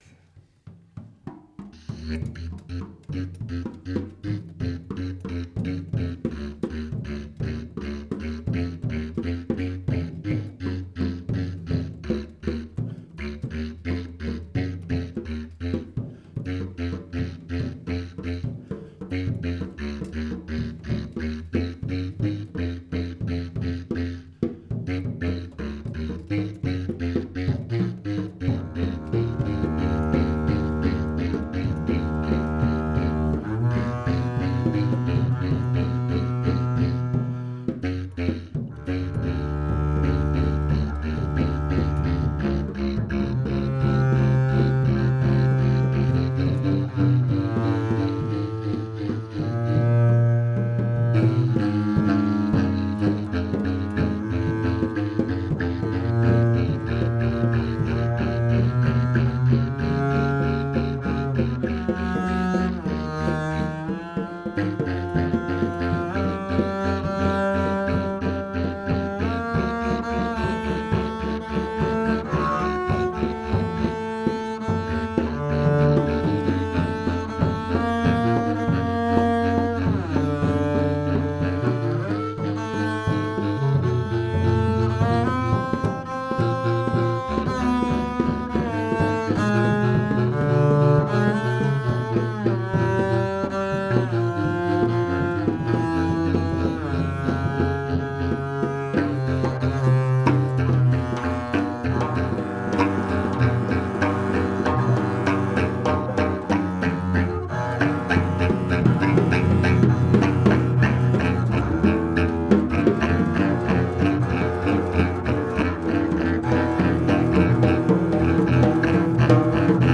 recorded live 9 March, 2019 at Splendor, Amsterdam